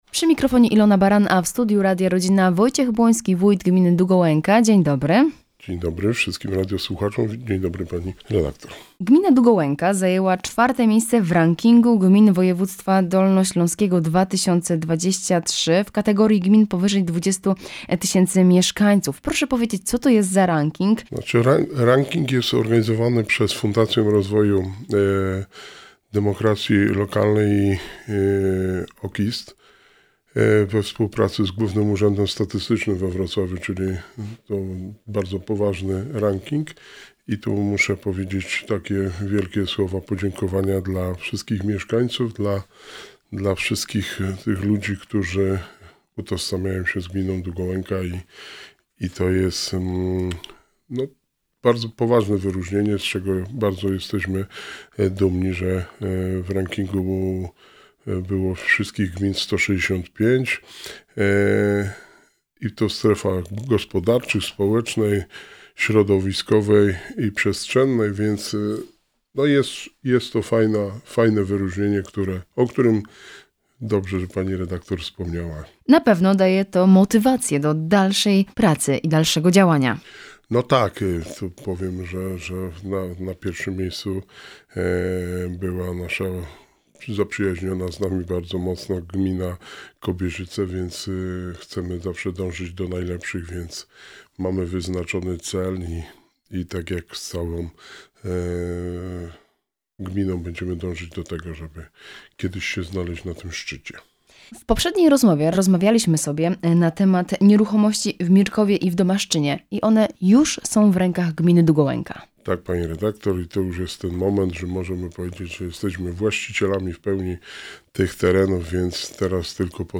W studiu Radia Rodzina gościł Wojciech Błoński, wójt Gminy Długołęka. Opowiedział o przystanku Mirków, IV miejscu Gminy Długołęka w Rankingu Gmin Województwa Dolnośląskiego 2023 oraz o nieruchomościach w Mirkowie i Domaszczynie.